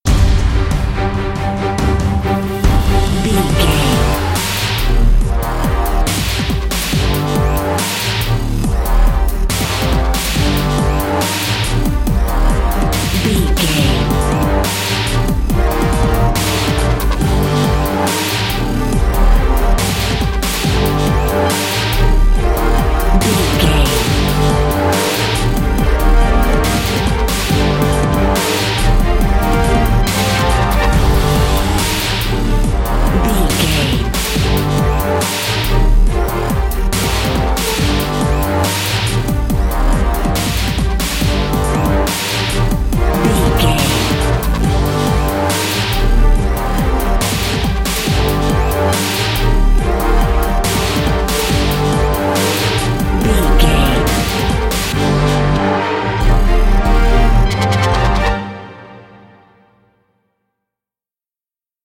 Aeolian/Minor
D
Fast
strings
drum machine
horns
orchestral
orchestral hybrid
dubstep
aggressive
energetic
intense
synth effects
wobbles
driving drum beat